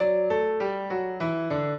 piano
minuet11-9.wav